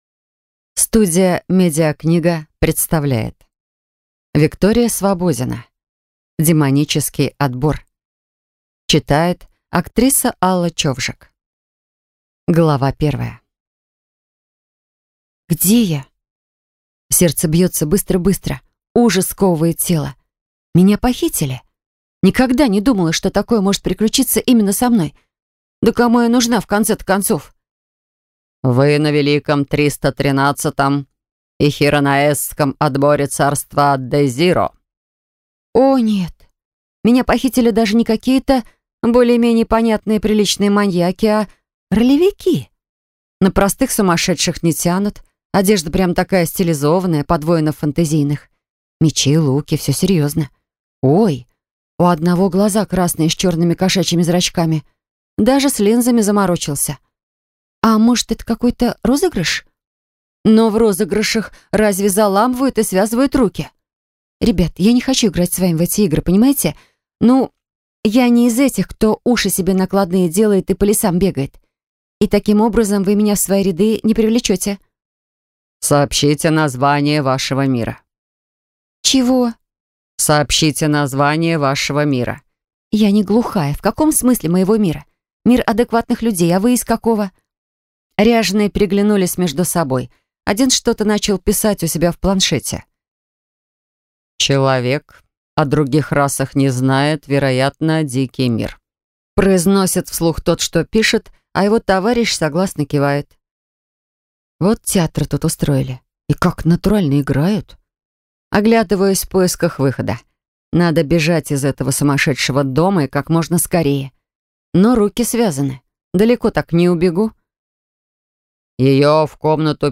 Аудиокнига Демонический отбор | Библиотека аудиокниг